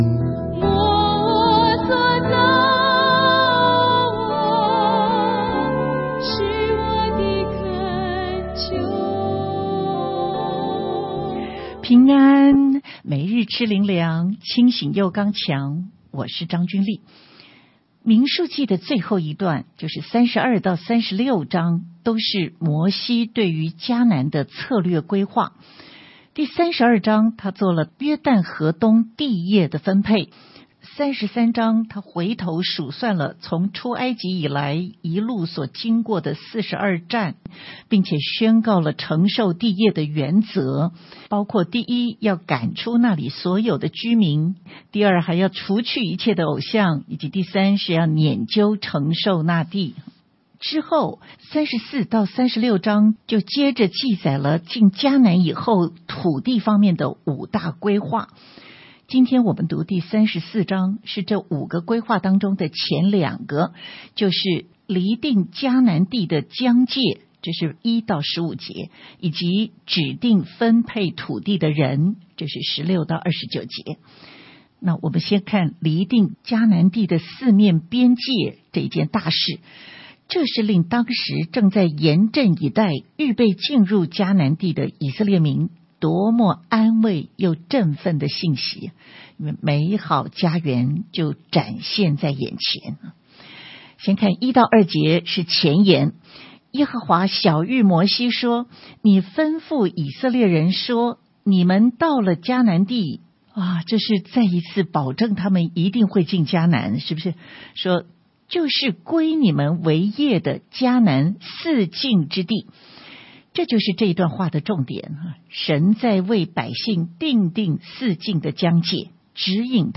出处：佳音电台